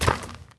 pedology_clay_footstep.4.ogg